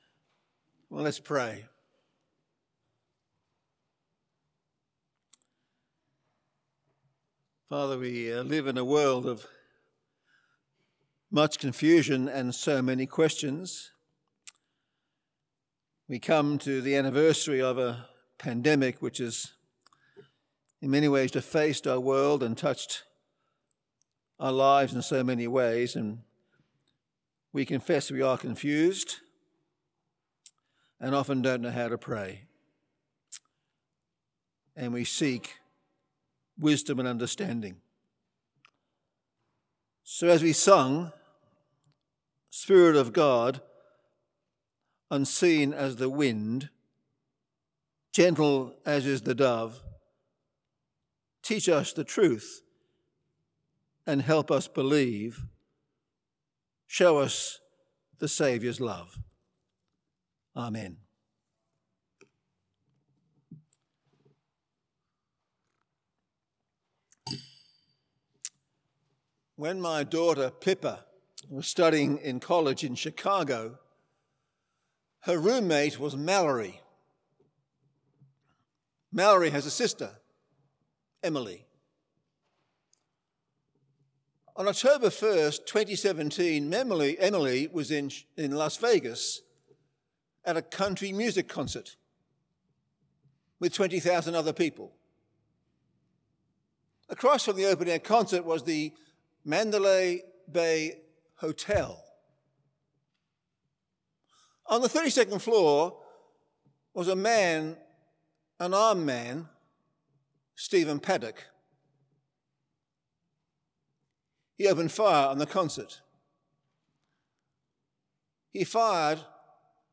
Full Service Audio
The Scots’ Church Melbourne 11am Service 31st of January 2021